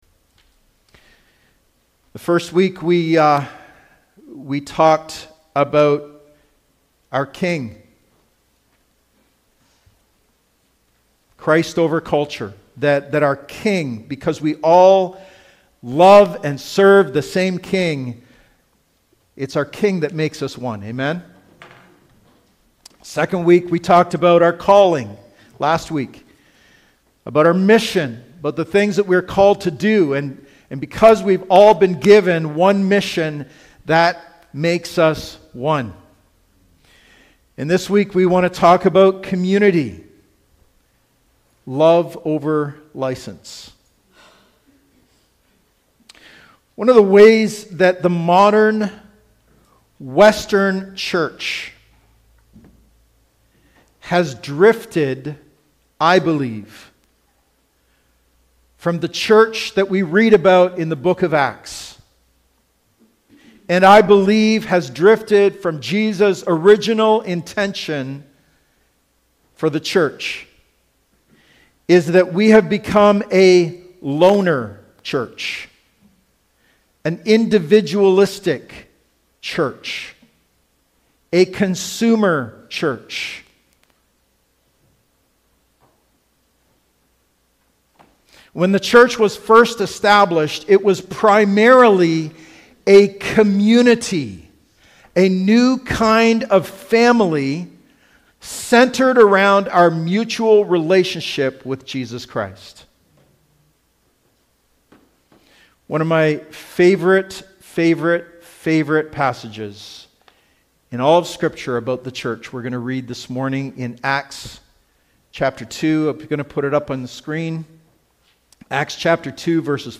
Listen to our weekly Sunday messages to help you grow in your walk with Jesus.